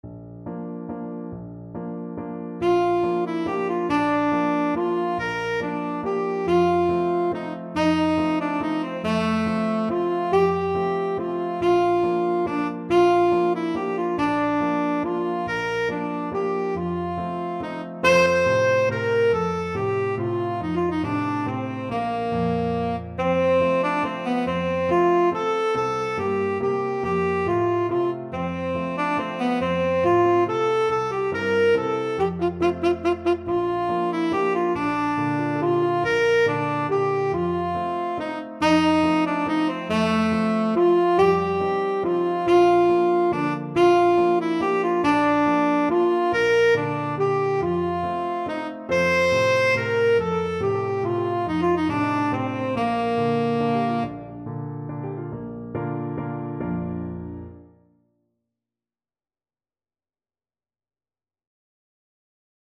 Allegretto = c.140
3/4 (View more 3/4 Music)
Neapolitan Songs for Tenor Sax